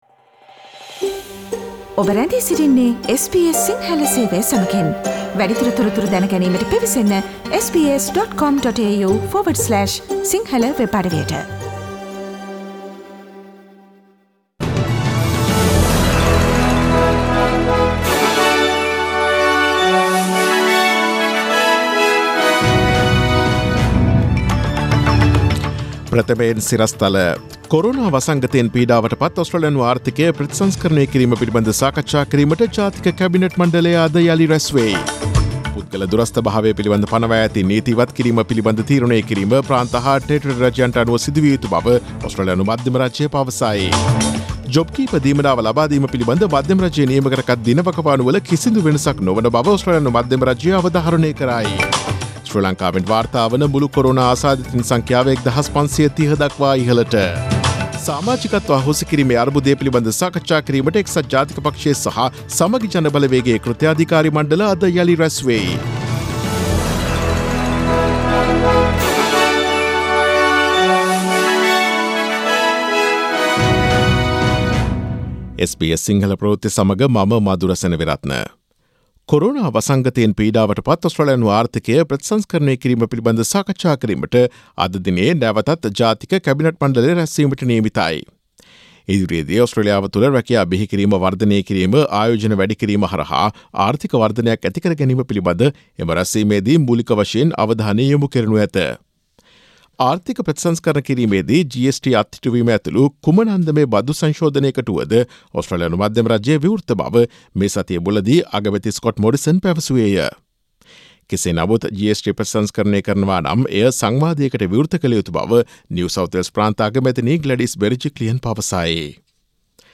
Daily News bulletin of SBS Sinhala Service: Friday 29 May 2020